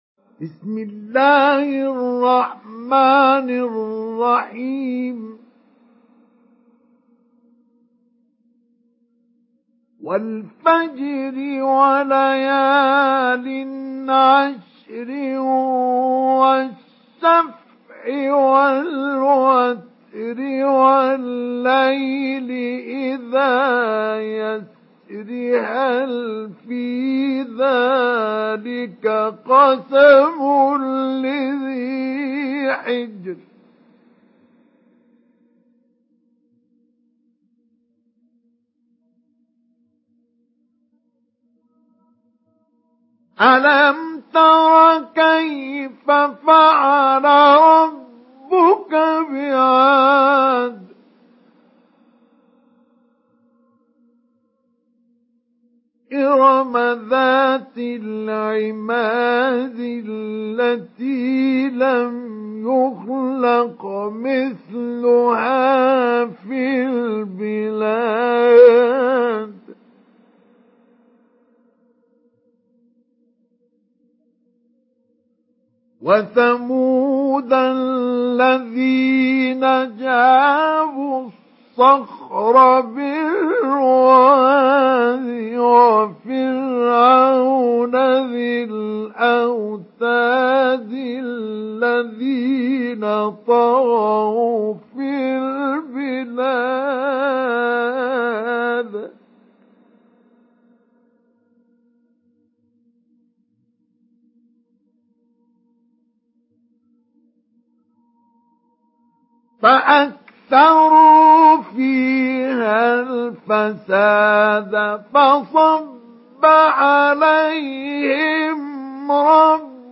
سورة الفجر MP3 بصوت مصطفى إسماعيل مجود برواية حفص